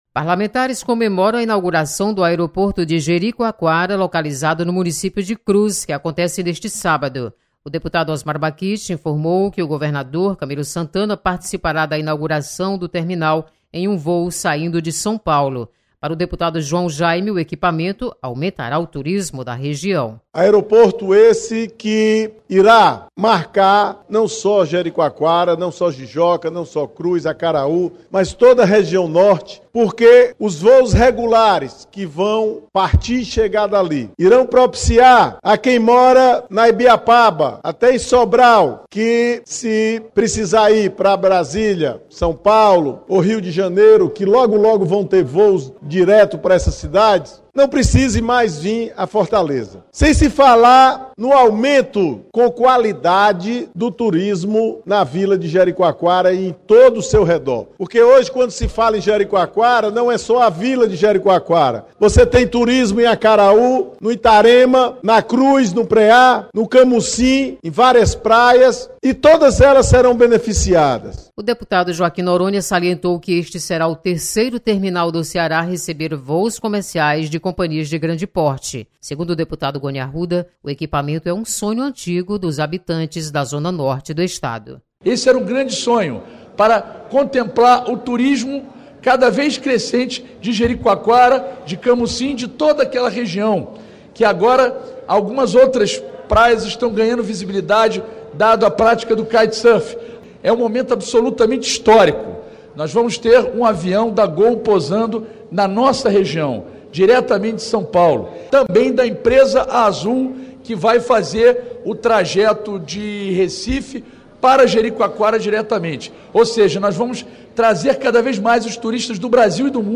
Deputados enaltecem inauguração de aeroporto em Jericoacoara. Repórter